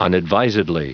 Prononciation du mot unadvisedly en anglais (fichier audio)
Prononciation du mot : unadvisedly